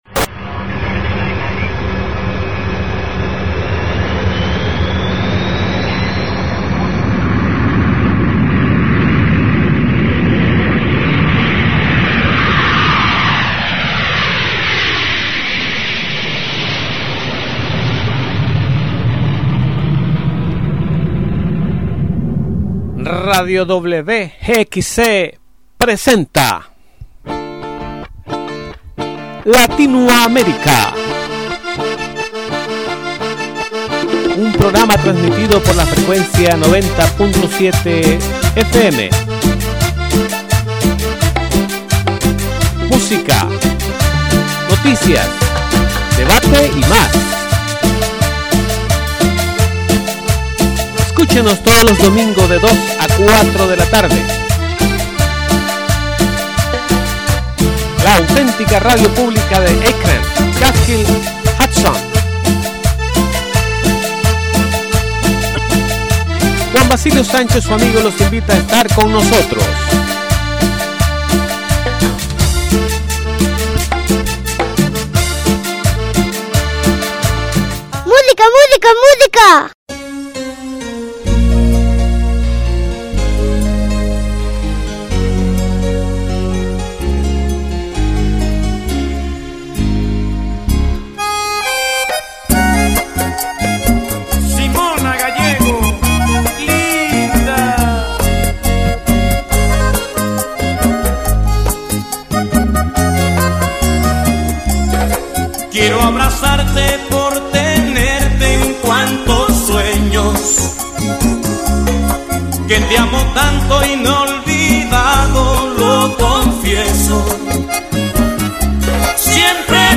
latinoaamericasept4del11musicayjingles.mp3